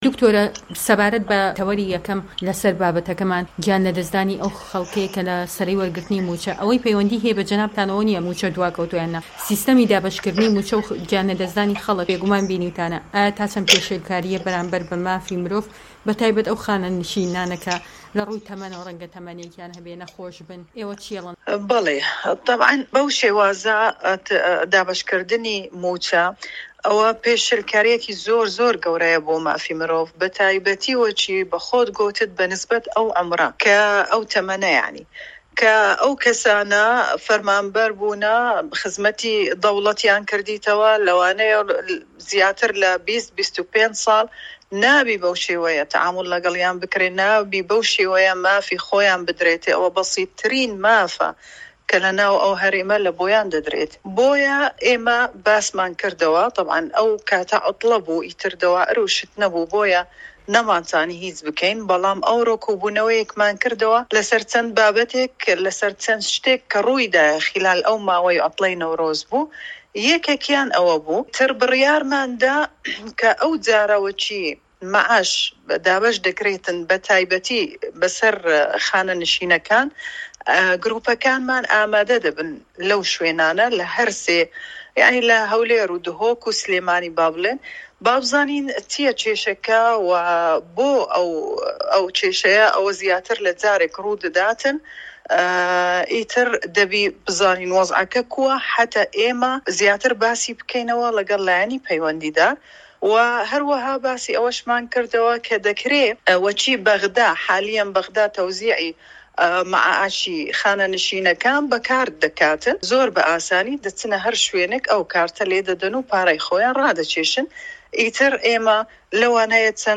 دەقی وتووێژەکەی د.مونا یاقوو سەرۆکی دەستەی مافی مرۆڤ Mar 29-022